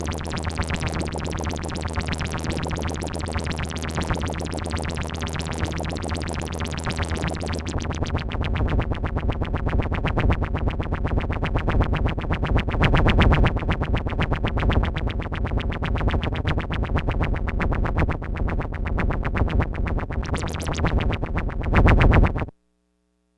Index of /90_sSampleCDs/E-MU Producer Series Vol. 3 – Hollywood Sound Effects/Science Fiction/Scanners
SCANNER 1-L.wav